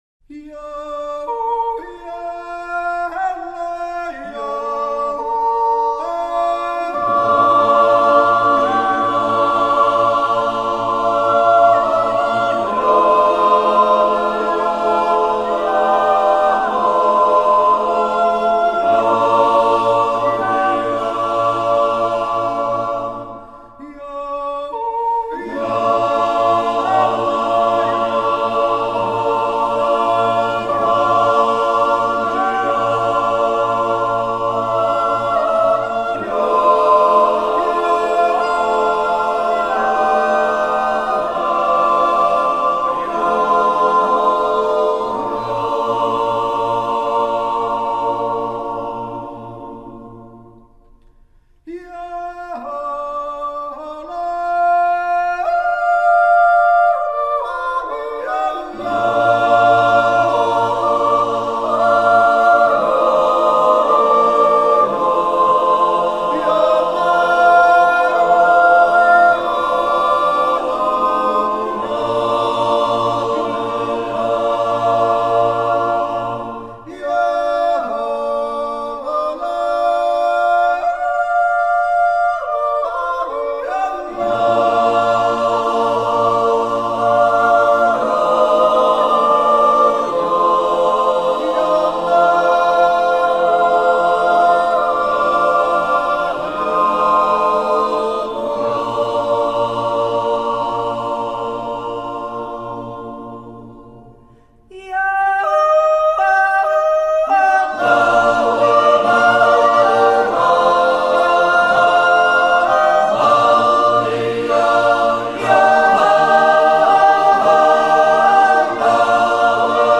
Yodelling is a style of singing which alternates between chest and head voices using syllables with no meaning.
Es Bärgjützi (Natural Yodel)
Jodlerklub Oberhofen BE